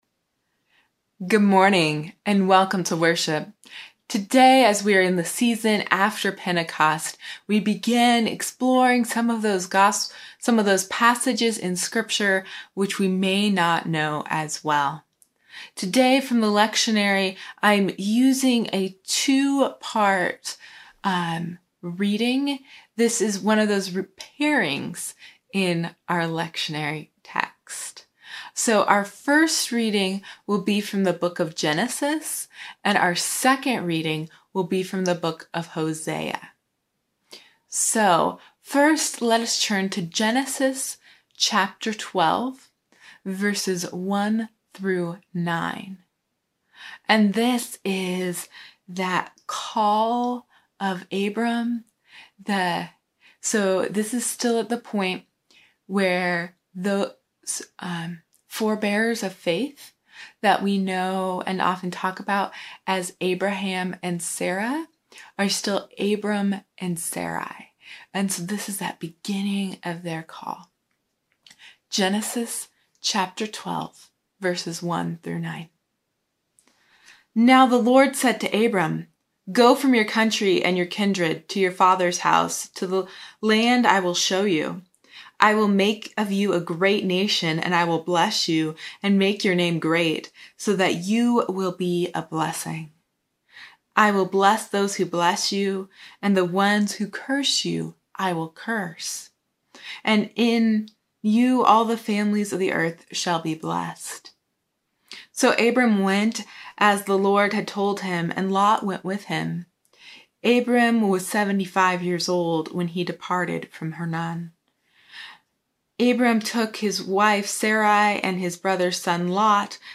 June-14-Sermon-Audio.mp3